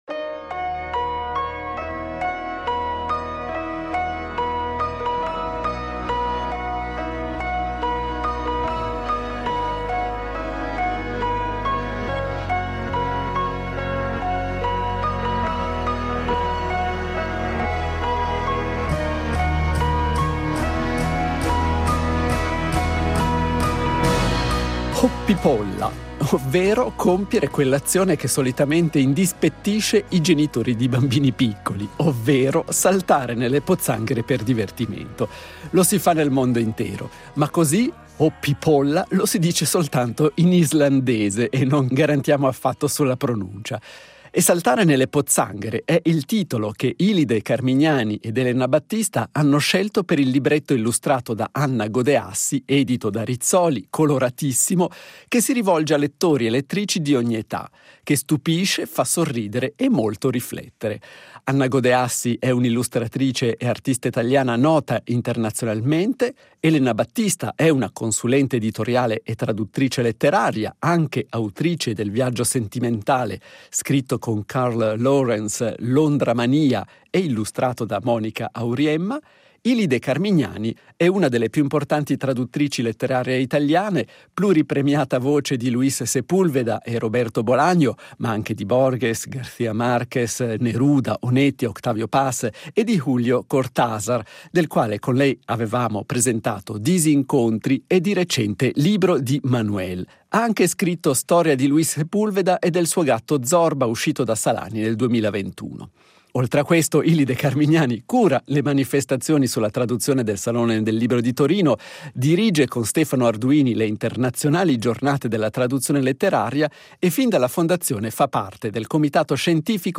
Intervista integrale.